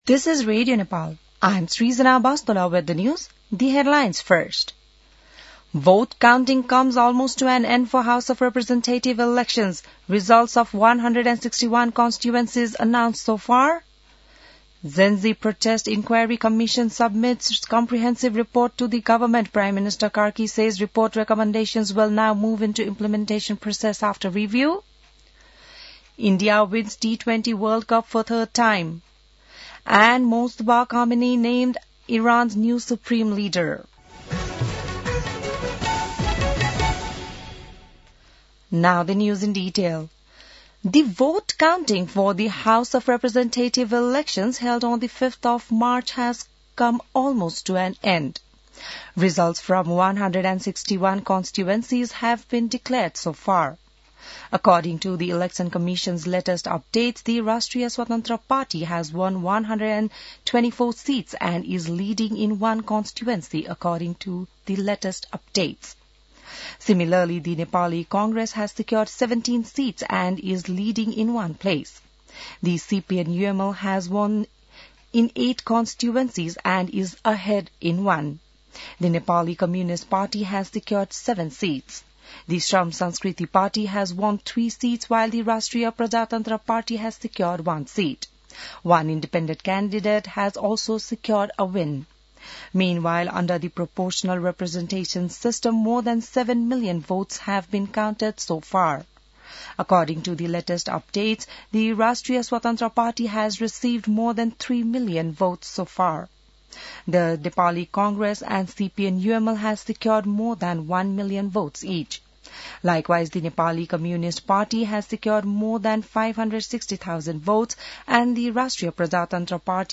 An online outlet of Nepal's national radio broadcaster
बिहान ८ बजेको अङ्ग्रेजी समाचार : २५ फागुन , २०८२